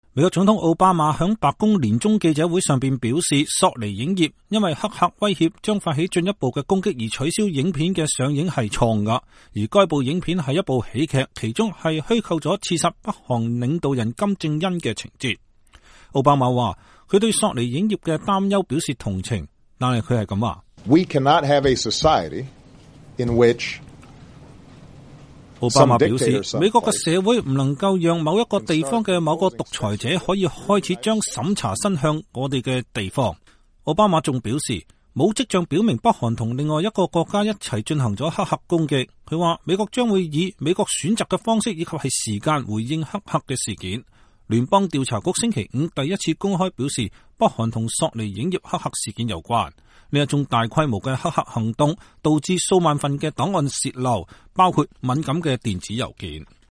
美國總統奧巴馬星期五在年終記者會上發表講話。